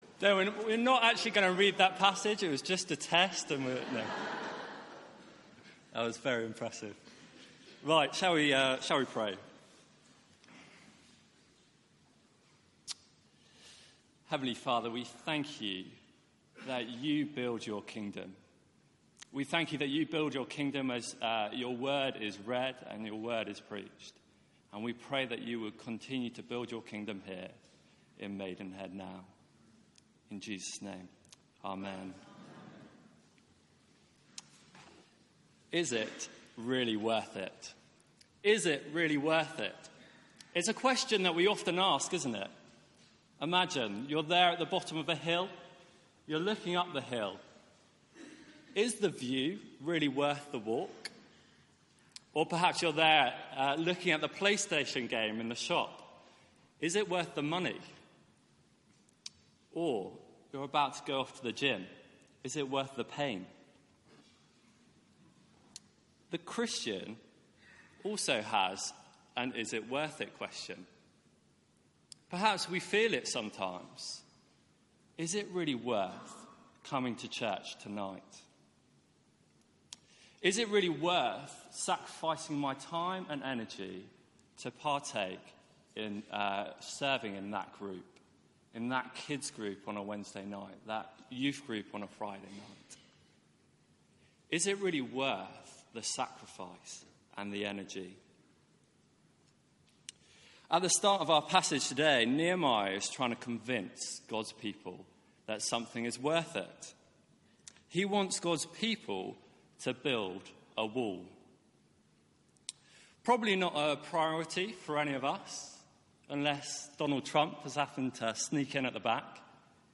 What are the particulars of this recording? Media for 6:30pm Service on Sun 30th Sep 2018 18:30 Speaker